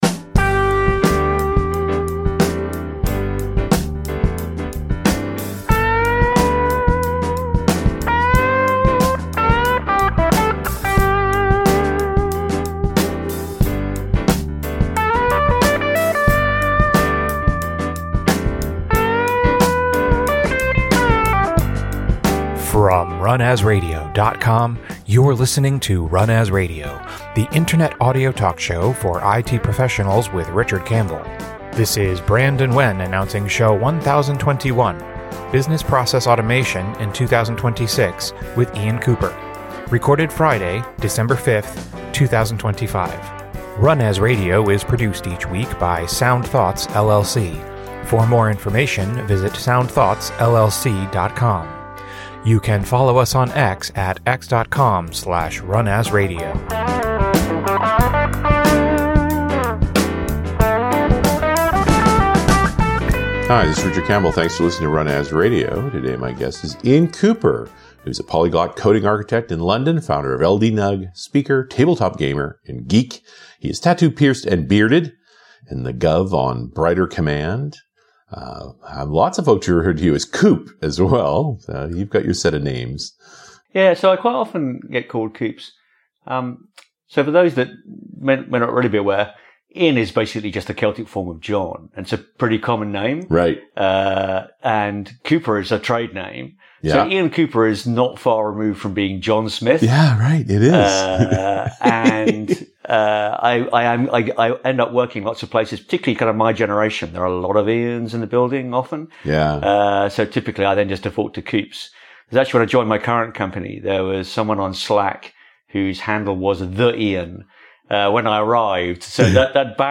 RunAs Radio is a weekly Internet Audio Talk Show for IT Professionals working with Microsoft products. The full range of IT topics is covered from a Microsoft-centric viewpoint.